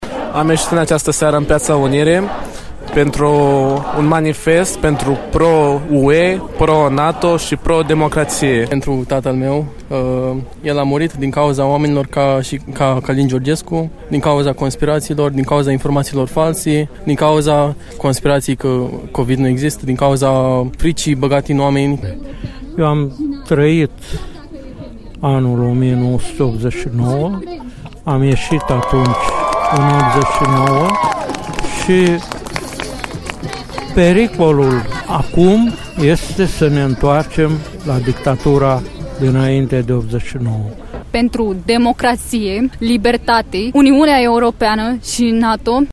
Aproximativ 350 de persoane s-au strâns în Piața Unirii și au scandat mesaje împotriva ideologiei legionare, pro Uniunea Europeană și pro NATO.
Piața-Unirii-Iași.mp3